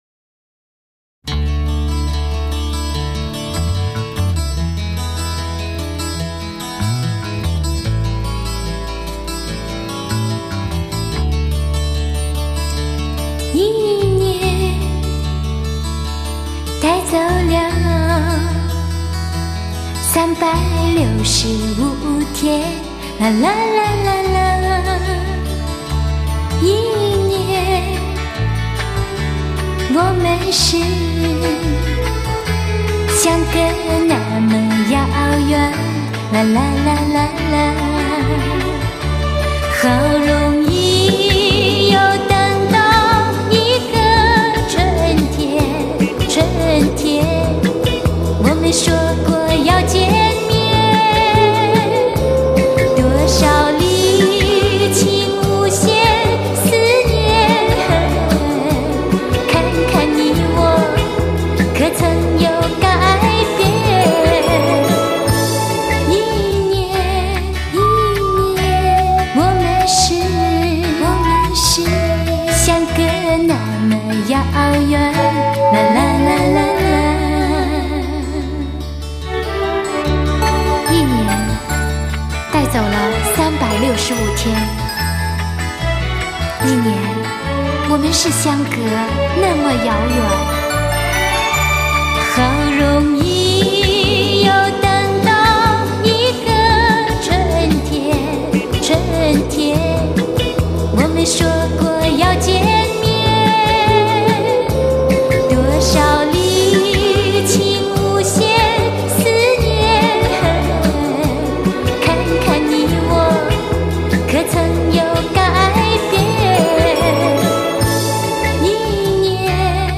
曲时，发音清晰、准确，  以及对歌曲的理解和把握都十分到位。